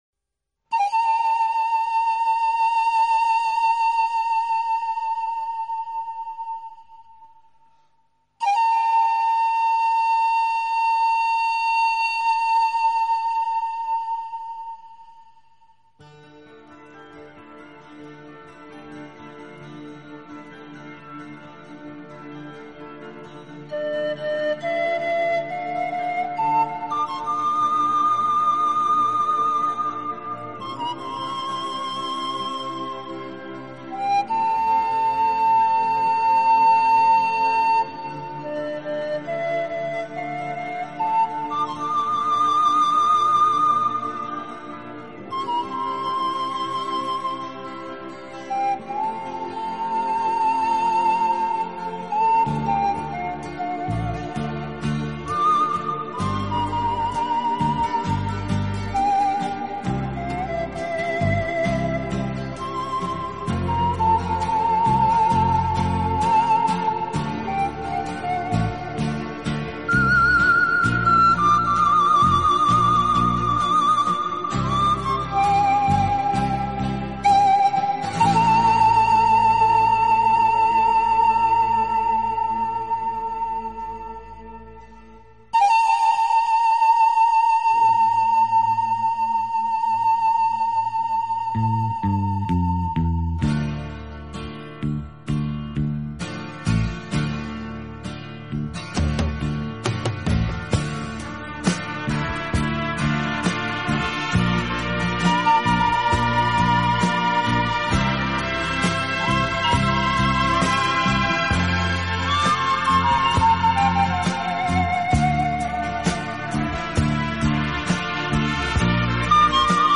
超广角音场的空间感演绎，大自然一尘不染的精华，仿佛让你远离凡尘嚣暄，